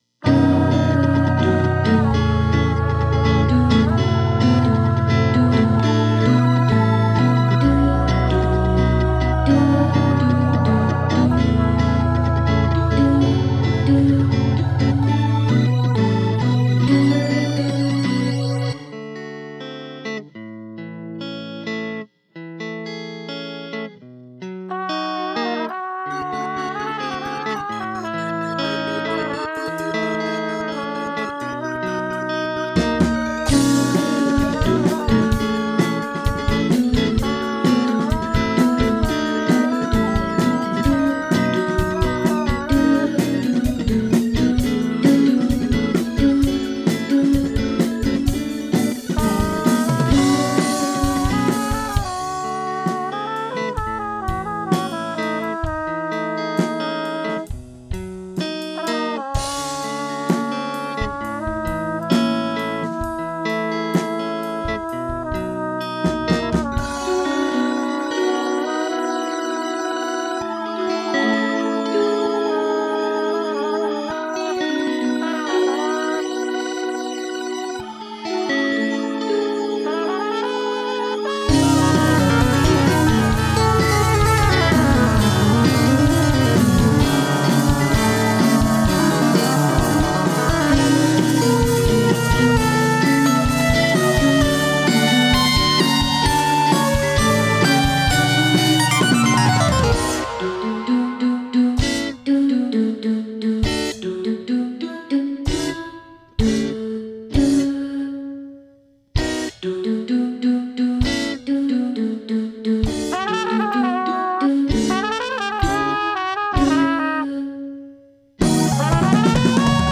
synthesizer
Synthesized vocals
electric guitar
Drums
Trumpet